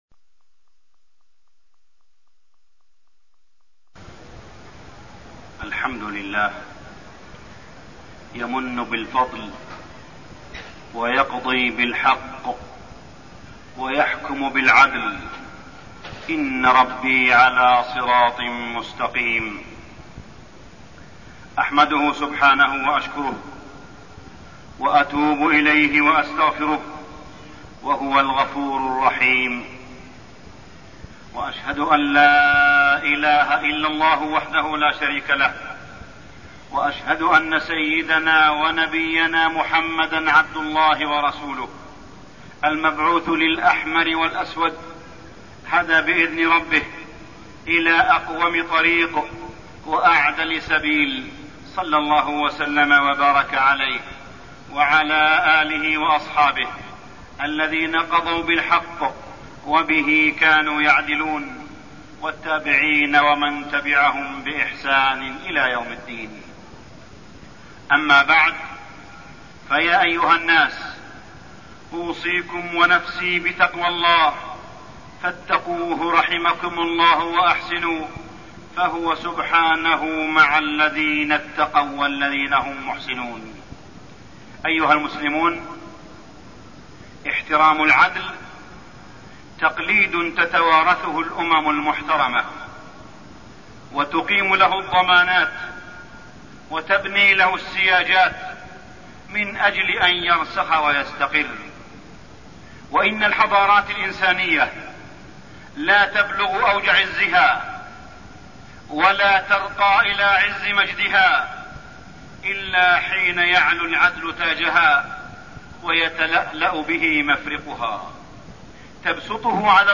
تاريخ النشر ١٧ جمادى الآخرة ١٤١٦ هـ المكان: المسجد الحرام الشيخ: معالي الشيخ أ.د. صالح بن عبدالله بن حميد معالي الشيخ أ.د. صالح بن عبدالله بن حميد العدل